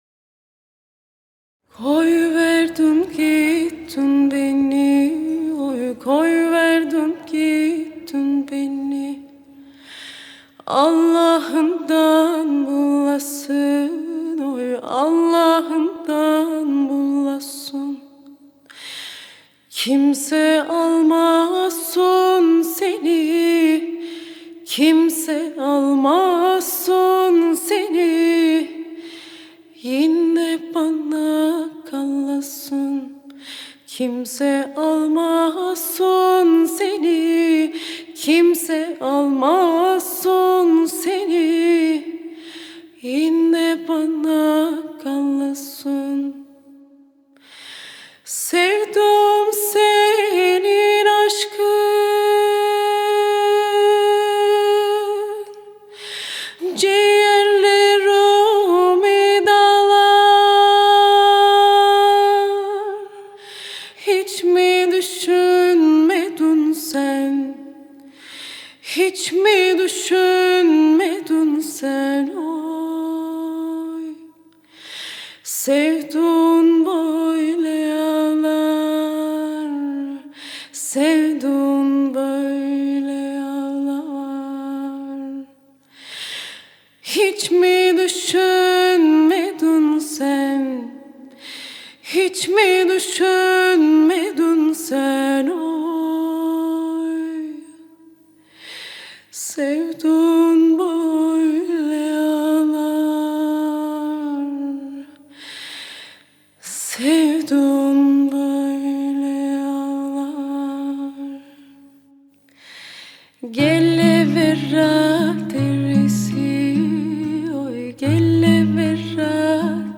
dizi müziği, duygusal hüzünlü üzgün şarkı.